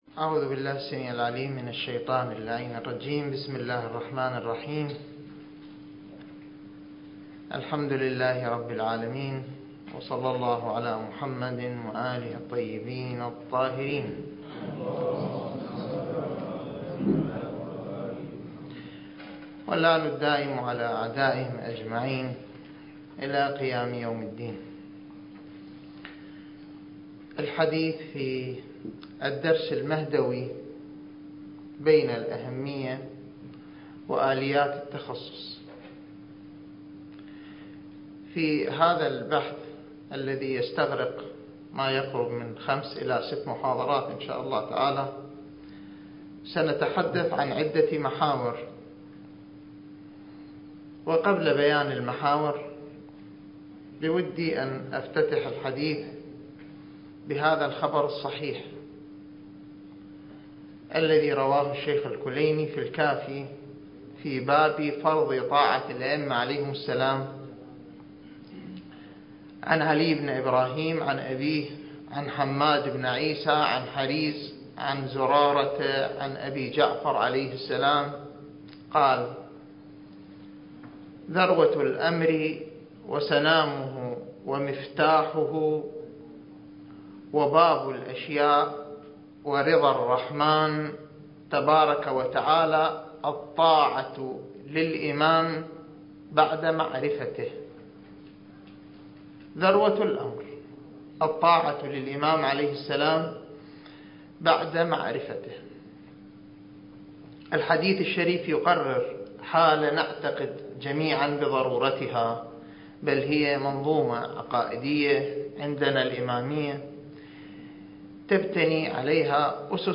(المحاضرة السادسة والعشرون)
المكان: النجف الأشرف